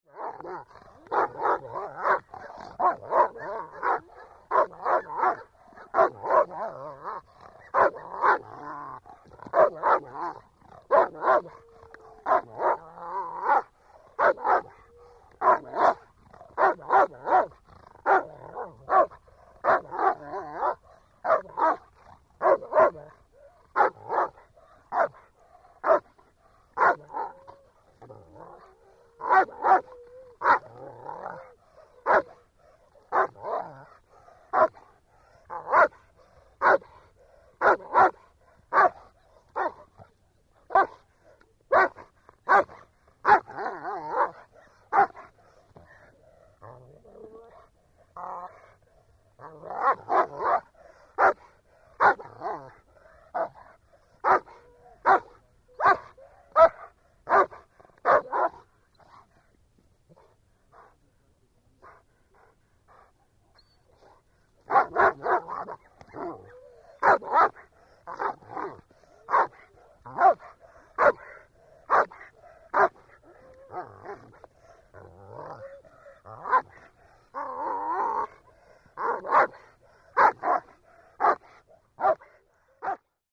Звуки немецкой овчарки
Звук лая немецкой овчарки на узников концлагеря